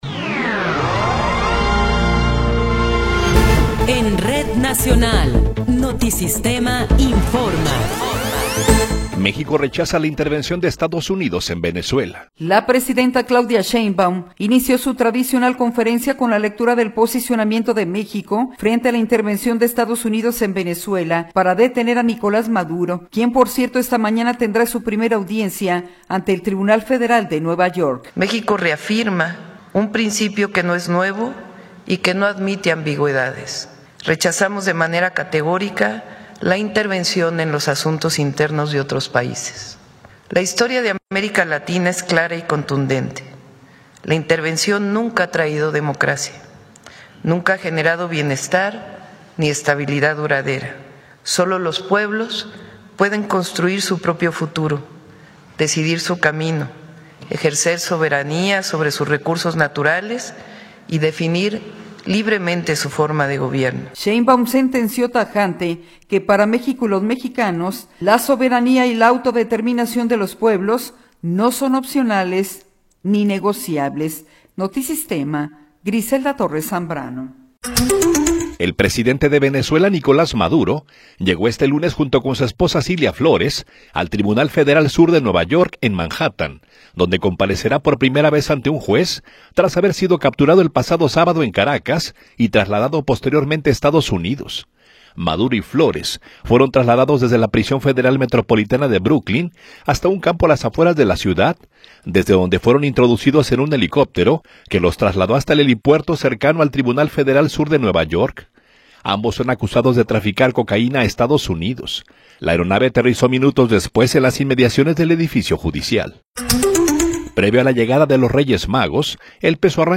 Noticiero 10 hrs. – 5 de Enero de 2026